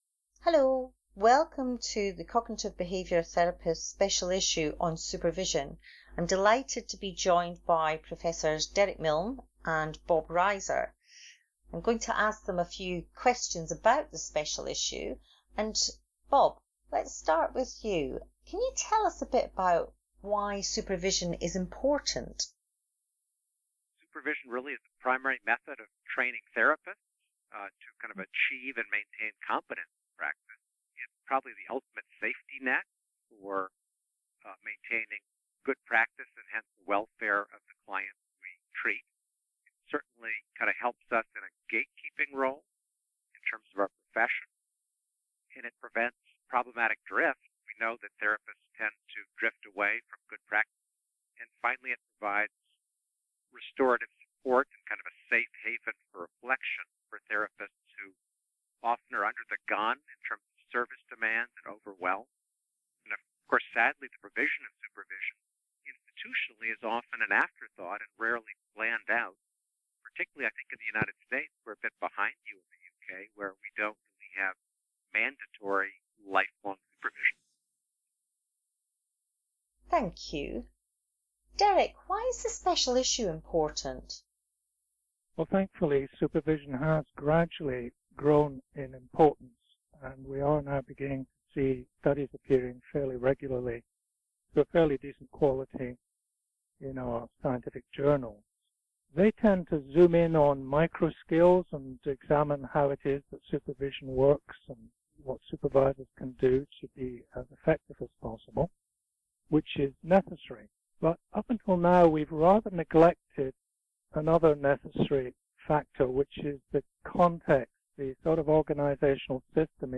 Listen to the introductory Special Issue podcast with the Guest Editors using the player below (or download here).